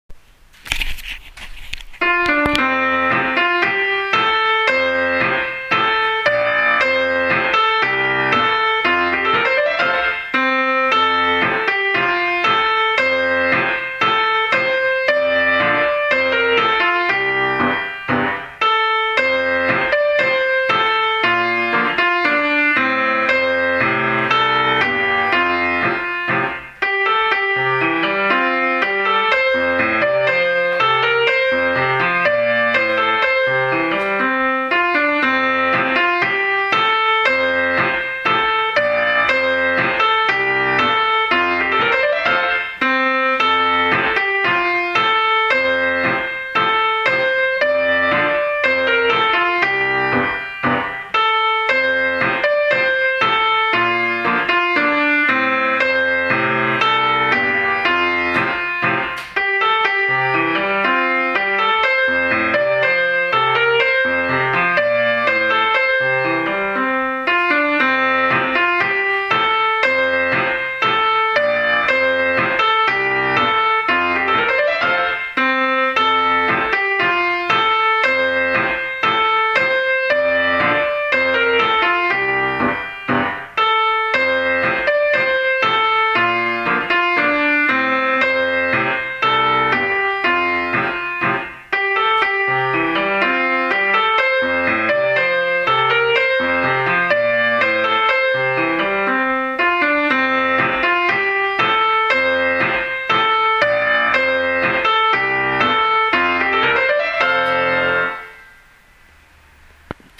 校歌伴奏